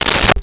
Umbrella Open 01
Umbrella Open 01.wav